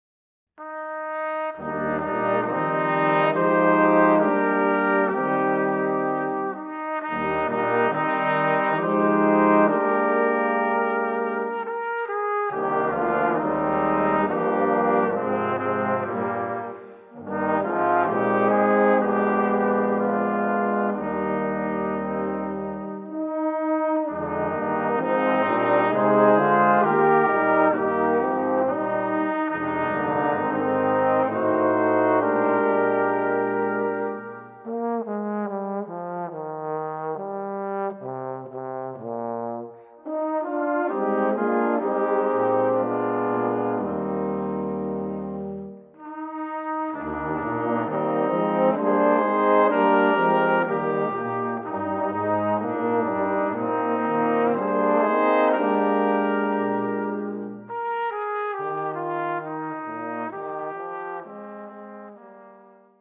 For Brass Quintet, Composed by Traditional English Carol.